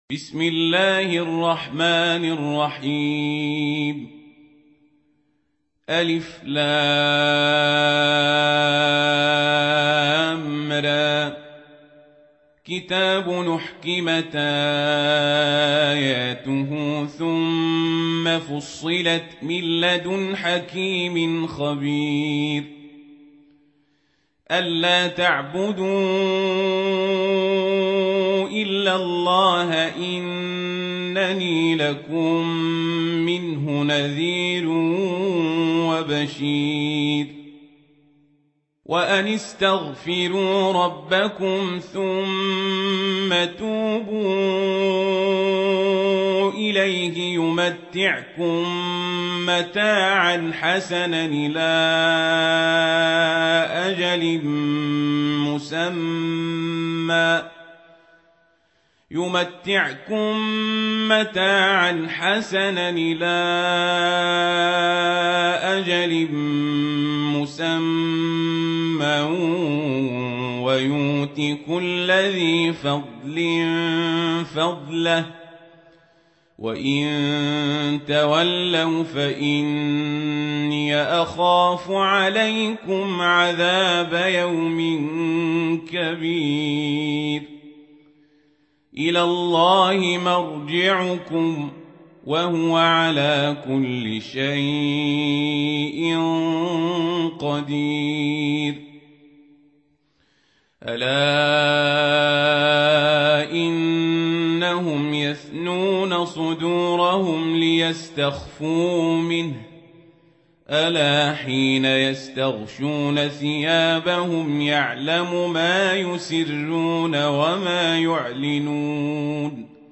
سورة هود | القارئ عمر القزابري